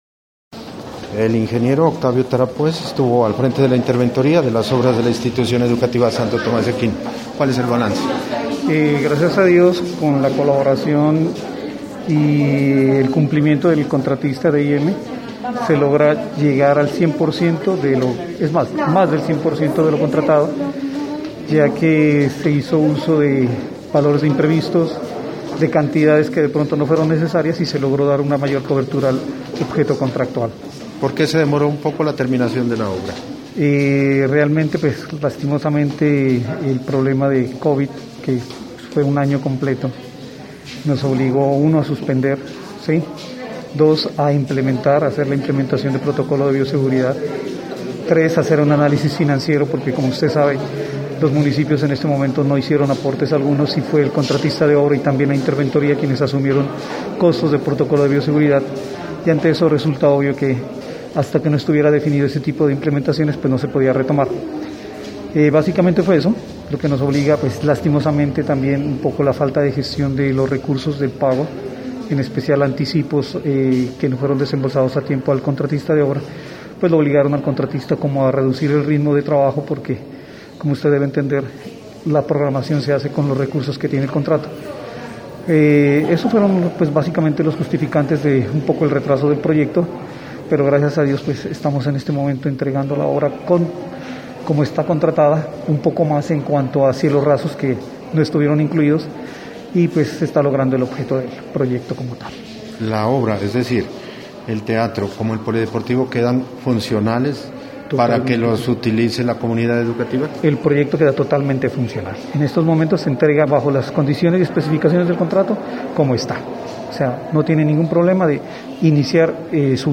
Los ingenieros responsables del proyecto de adecuación del salón de actos y construcción de la cubierta del polideportivo de la Institución educativa Tomás de Aquino de Sandoná hicieron entrega de las obras este viernes en horas de la mañana.